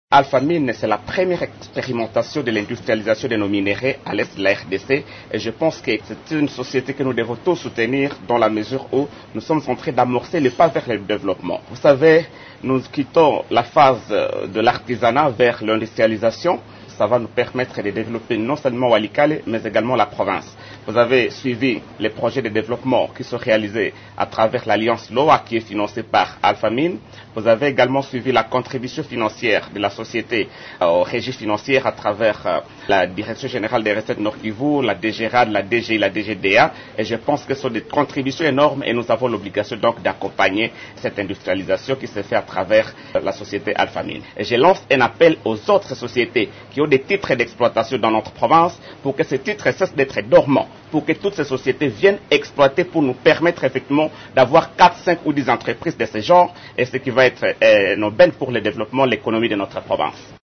« C’est la première expérimentation de l’industrialisation de nos minerais dans l’Est de la RDC. C’est une société que nous devons soutenir parce que nous amorçons le pas vers le développement », a-t-il indiqué, à l’occasion de la journée minière organisée à Goma.
Suivez dans cet extrait sonore les propos du ministre provincial des Mines.